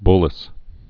(blĭs)